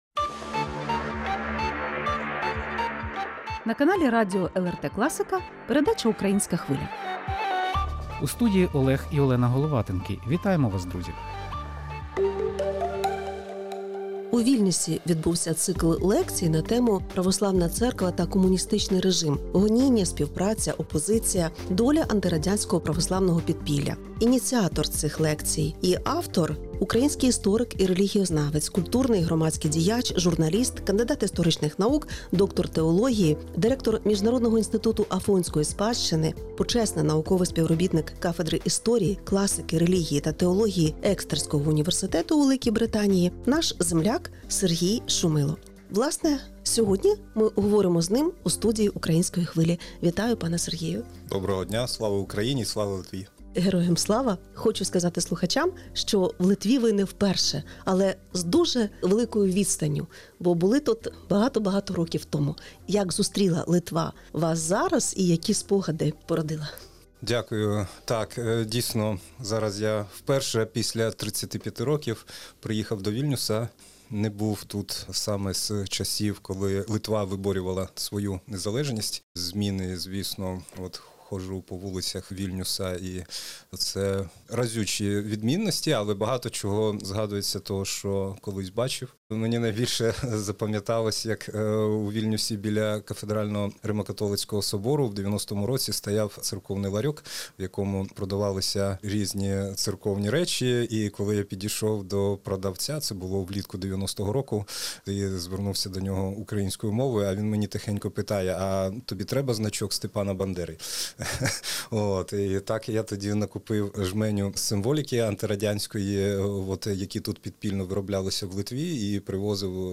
У передачі «Українська Хвиля» спілкуємося з українським істориком, релігіознавцем, доктором теології